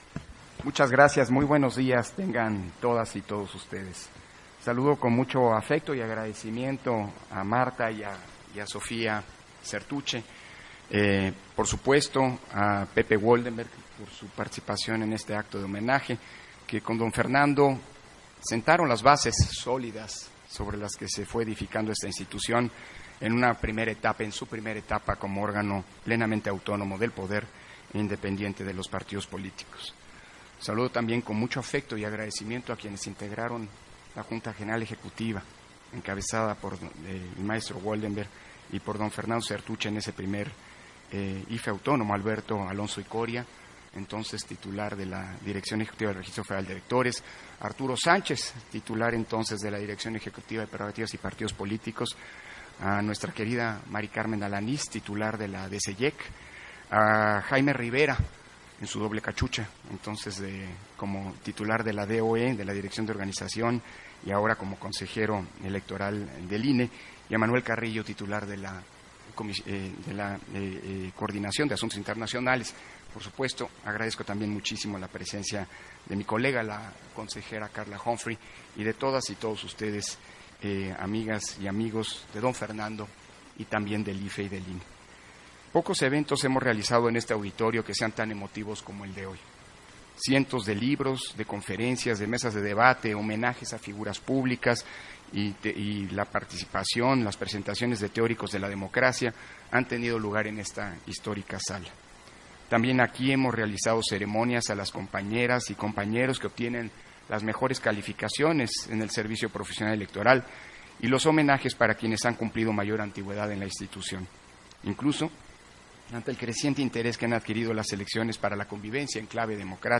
Intervención de Lorenzo Córdova, durante la inauguración del Foro: Presencia y legado IFE-INE en el México actual